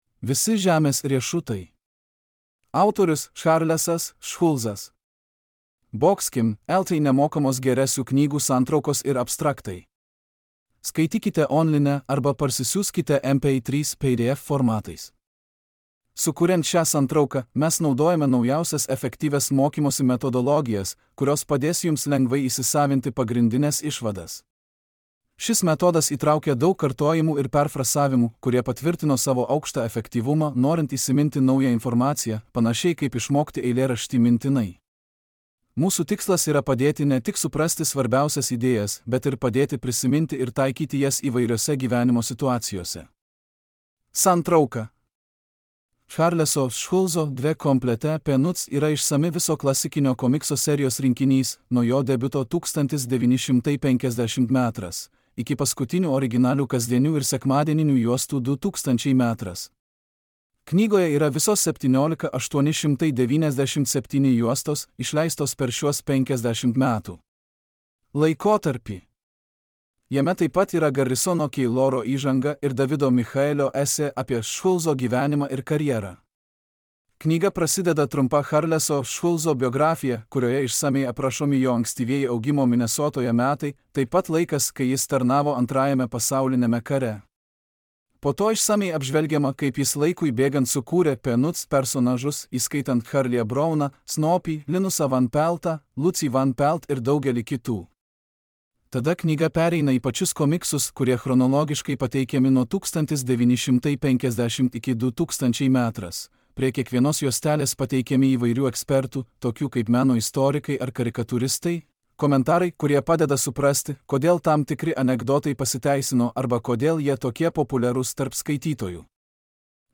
Charlesas Schulzas: Visi žemės riešutai | Knygos santrauka | Skaityti online arba atsisiųsti MP3 / PDF formatu be registracijos.